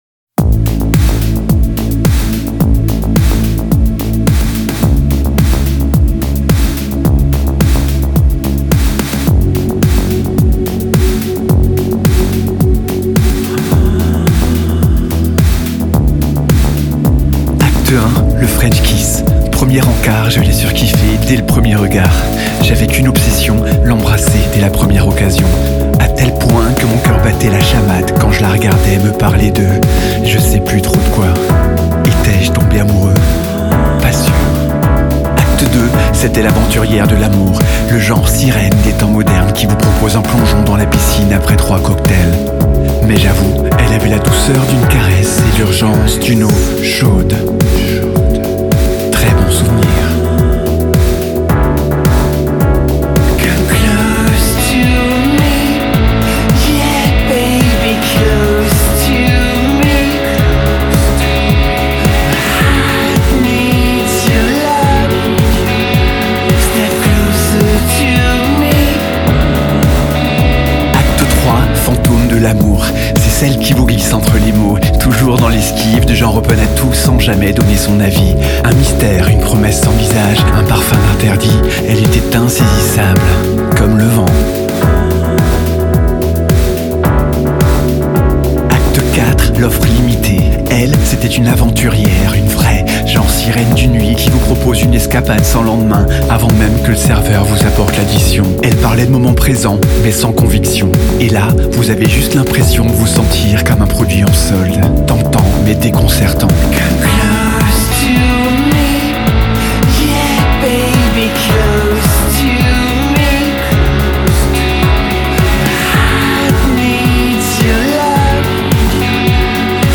Baryton